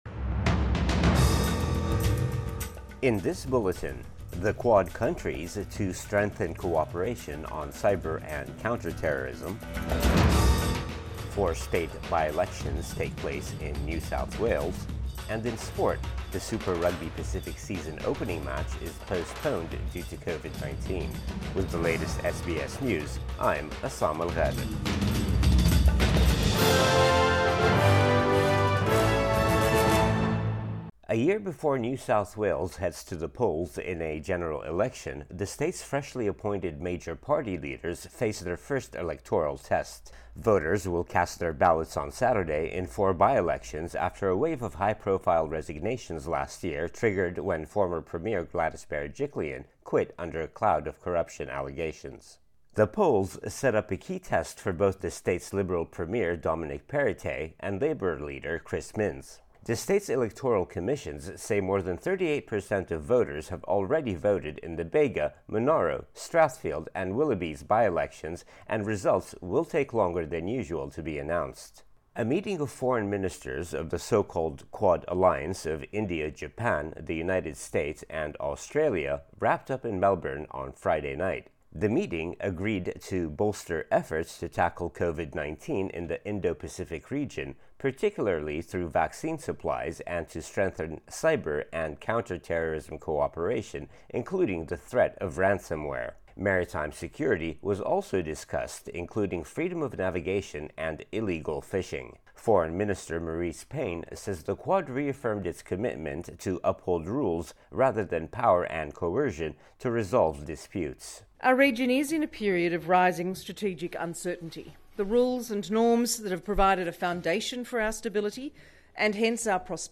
AM bulletin 12 February 2022